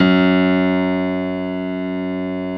55p-pno10-F#1.wav